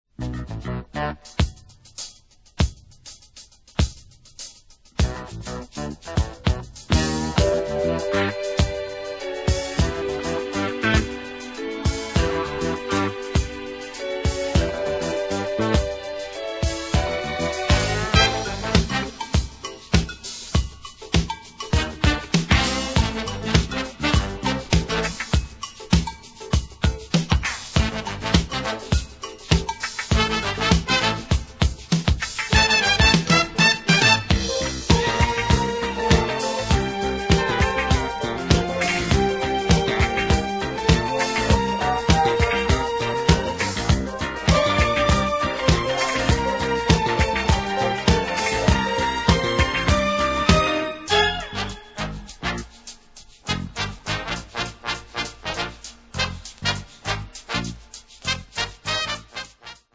Big percussion and a full, big band sound.